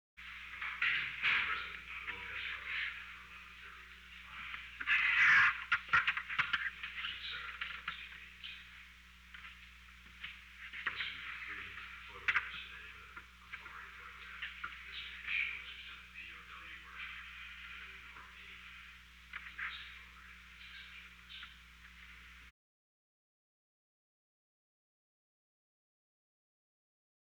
Secret White House Tapes
Conversation No. 893-7
Location: Oval Office